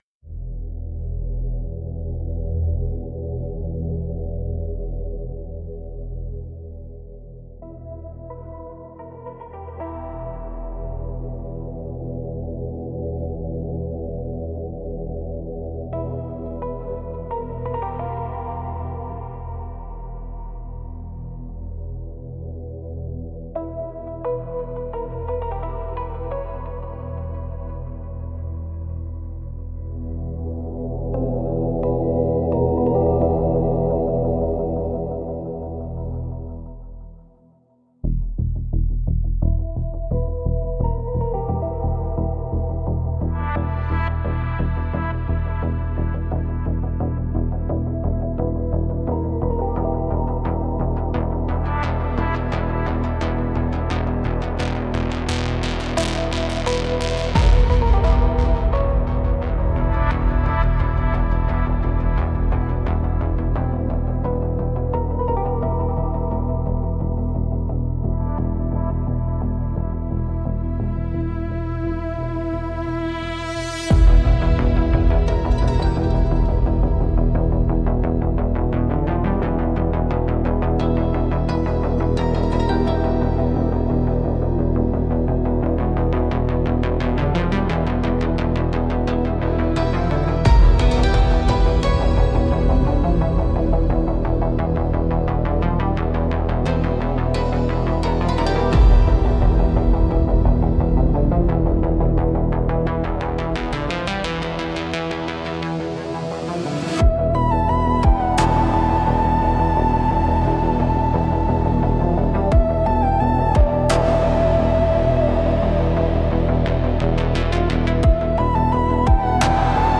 Instrumentals - The Slow Rust of Purpose